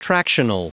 Prononciation du mot tractional en anglais (fichier audio)
Prononciation du mot : tractional